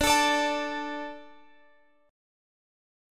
D 5th